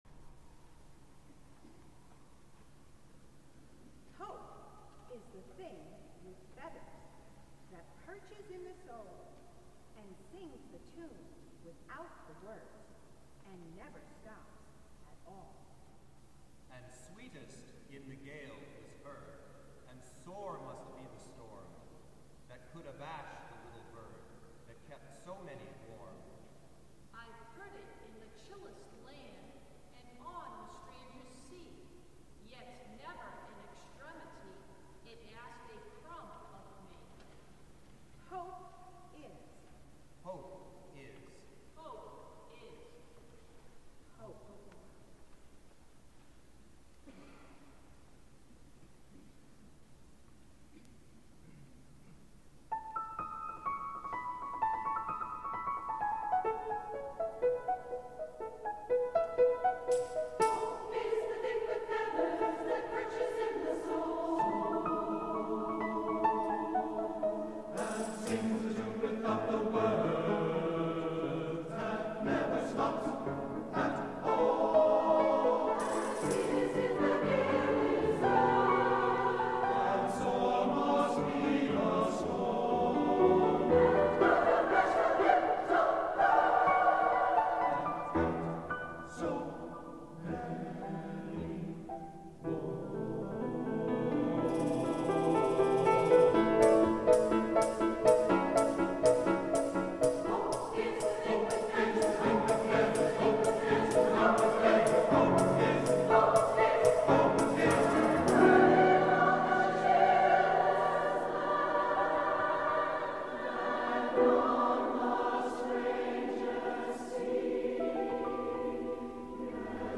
for SATB Chorus, Piano, and Opt. Percussion (2006)